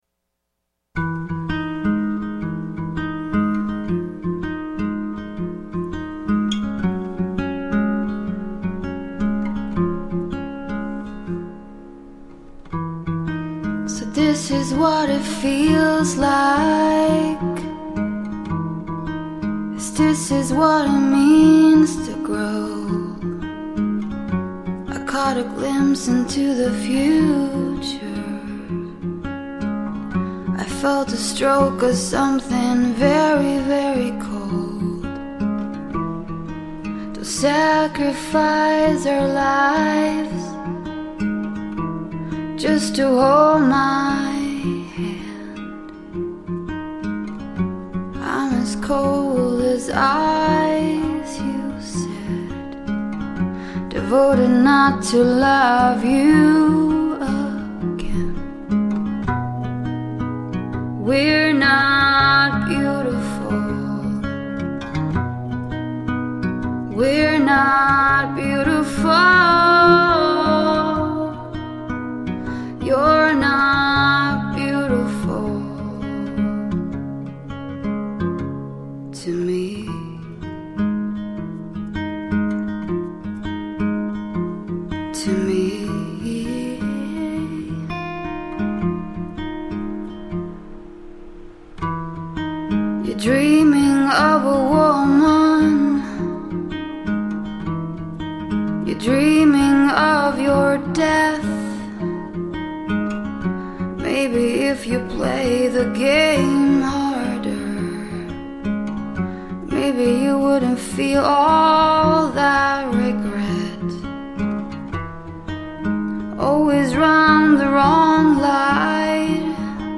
has an amazing voice